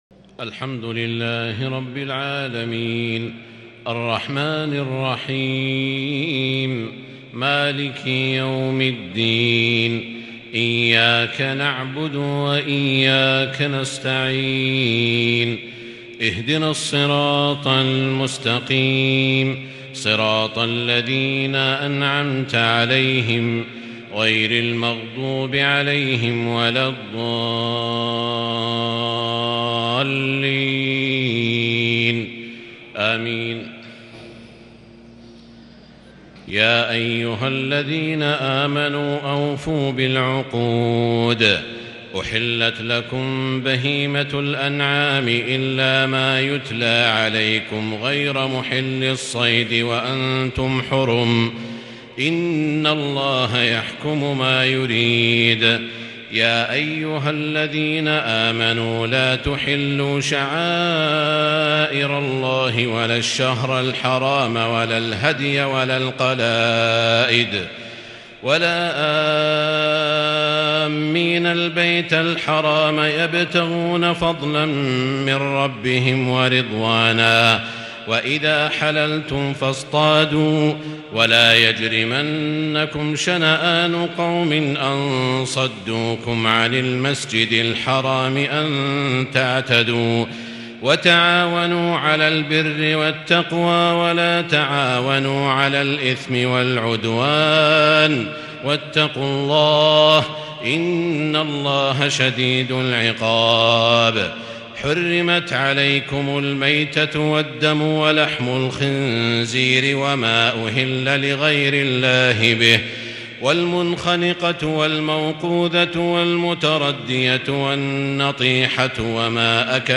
تراويح ليلة 8 رمضان 1442هـ من سورة المائدة {1-40} Taraweeh 8st night Ramadan 1442H Surah AlMa'idah > تراويح الحرم المكي عام 1442 🕋 > التراويح - تلاوات الحرمين